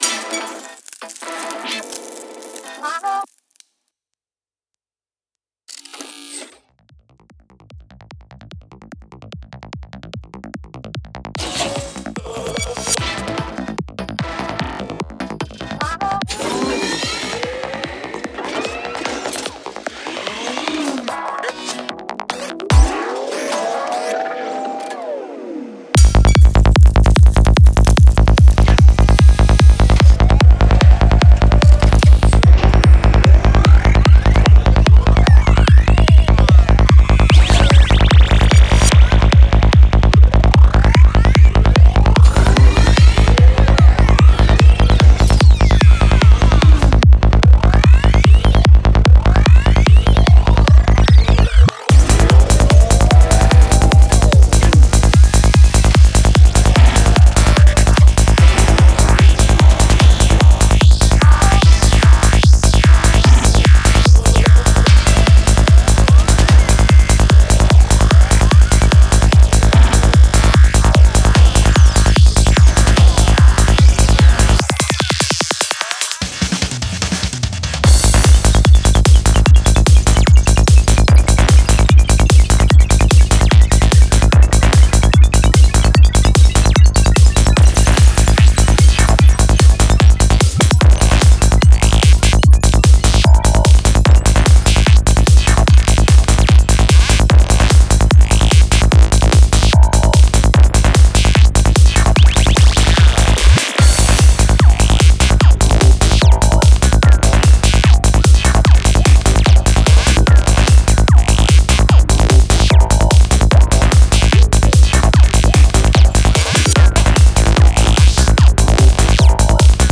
Genre: Goa, Psychedelic Trance.